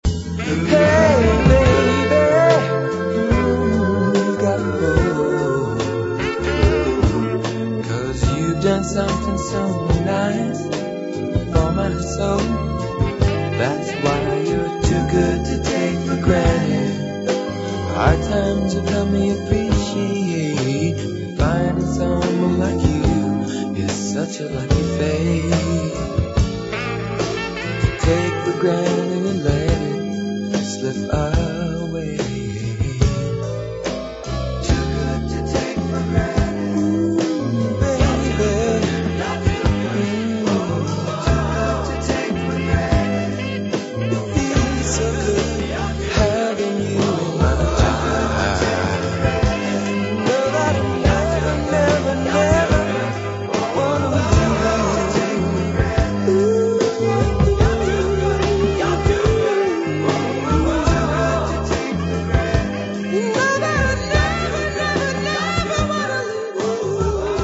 ホーンを導入し、ＮＲとは全く異なったファンキー・ロック・バンド。
メロウなＡＯＲ的要素も含まれております。